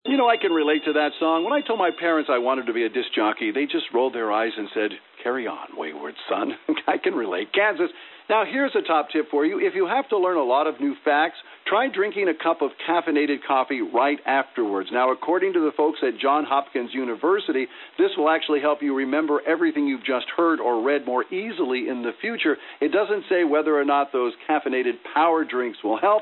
We noticed the recording level is a bit low in level and no “low end” of the audio spectrum (firmware 1.05). Frequency response is tailored more for voice communications, but is OK for broadcast station recordings.
Icom IC-R30 "FM Broadcast Audio File" as made with the built in recorder.
16 Bit MONO